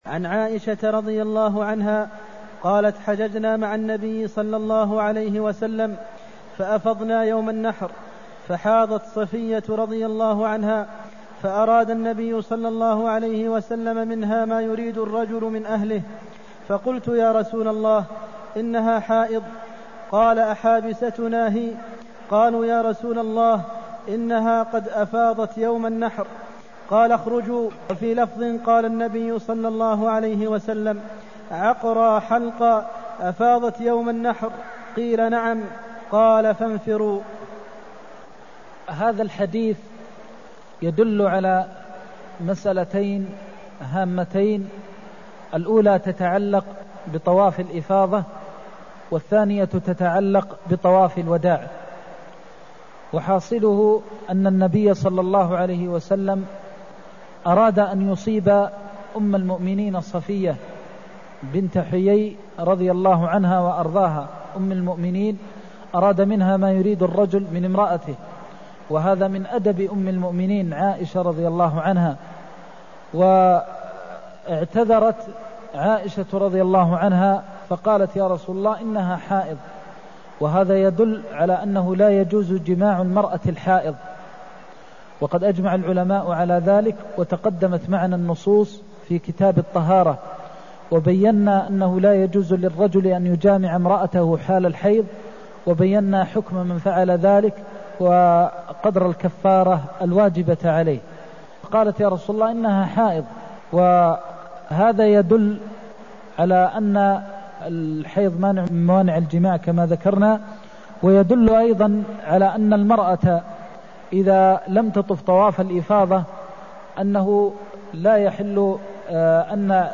المكان: المسجد النبوي الشيخ: فضيلة الشيخ د. محمد بن محمد المختار فضيلة الشيخ د. محمد بن محمد المختار أحابستنا هي (237)